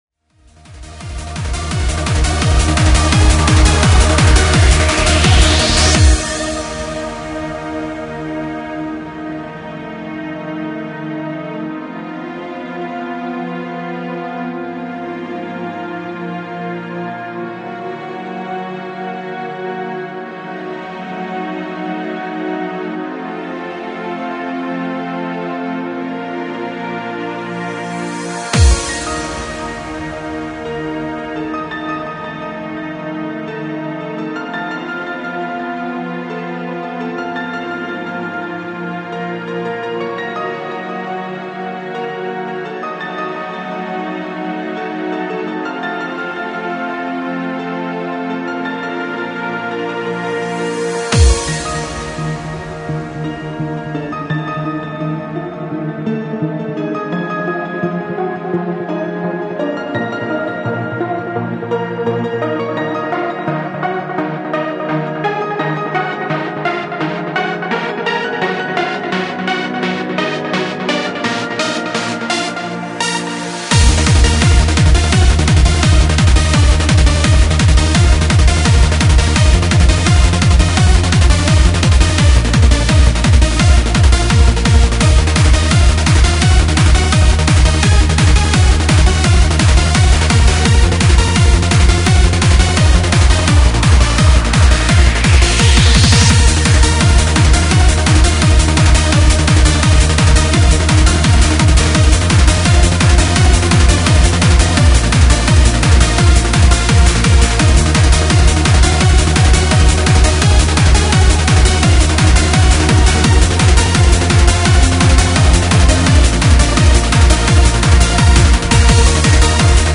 driving, classical, gothic trance atmospherics